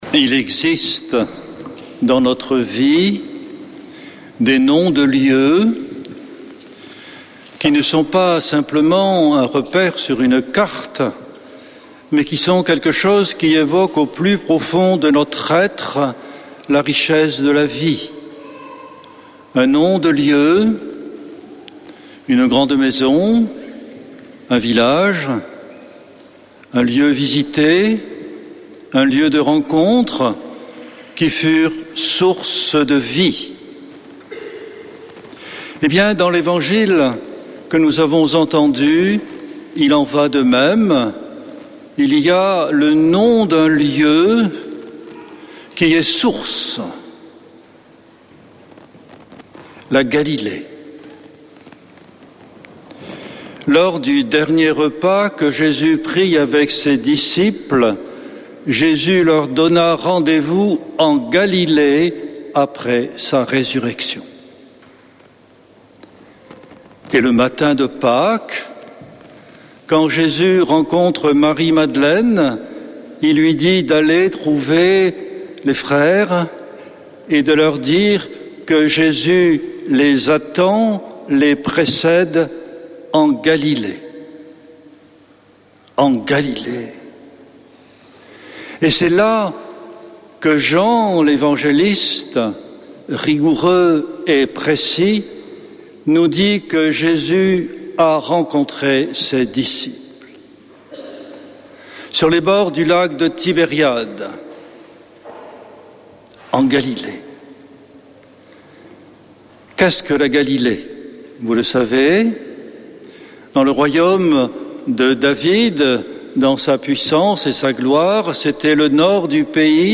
dimanche 4 mai 2025 Messe depuis le couvent des Dominicains de Toulouse Durée 01 h 28 min